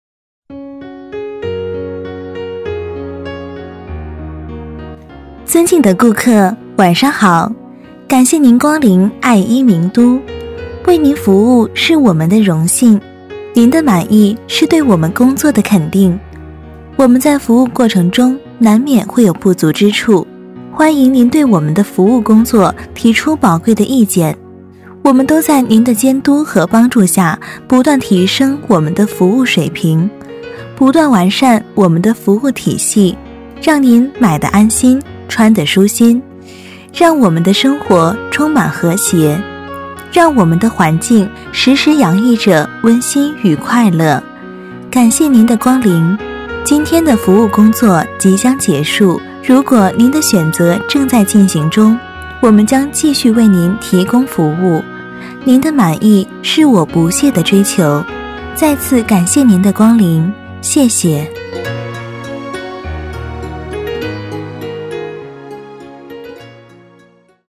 【女31号抒情】上班温馨播报
【女31号抒情】上班温馨播报.mp3